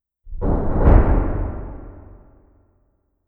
trueno_4.wav